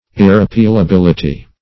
Search Result for " irrepealability" : The Collaborative International Dictionary of English v.0.48: Irrepealability \Ir`re*peal`a*bil"i*ty\, n. The quality or state of being irrepealable.